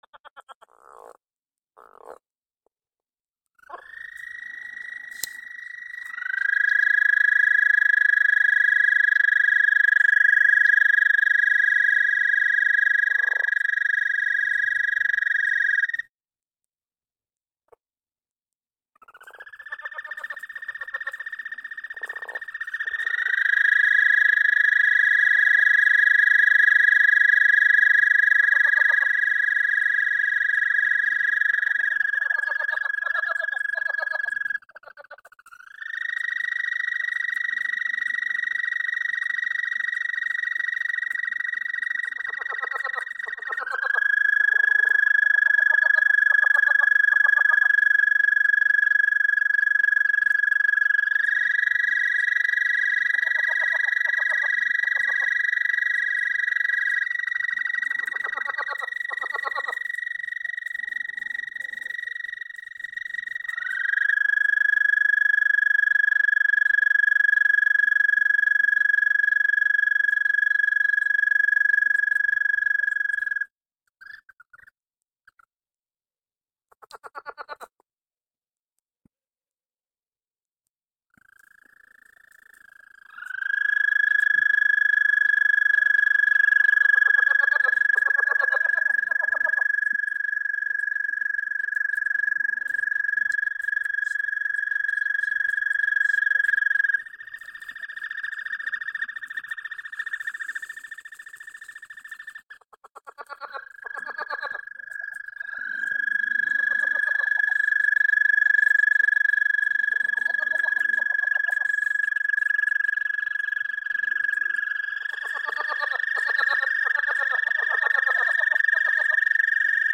Imagine laying back into tall green grass or a bed of clover next to a small pond with no sounds other than nature, enjoying an amazing clear night sky above, with no moon, an occasional meteor and the Andromeda Galaxy easily visible just south of Cassiopeia.
Frogs.mp3